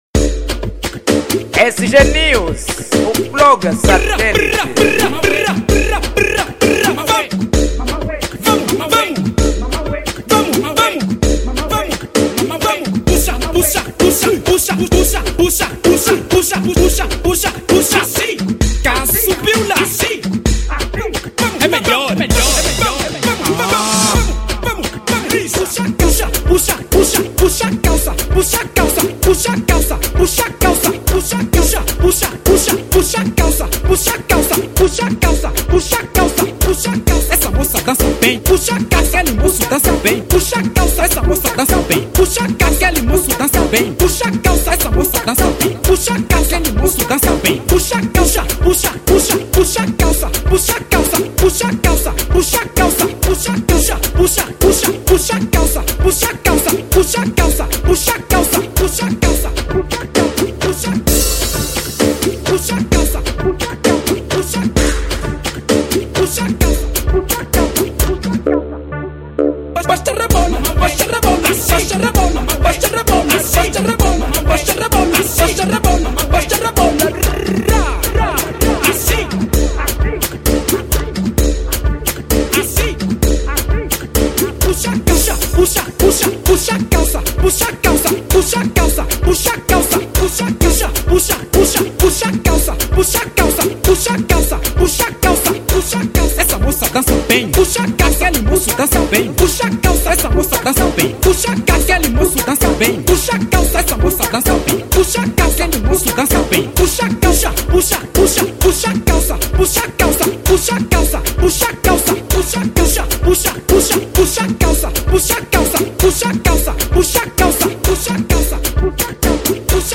Género : Afro Funk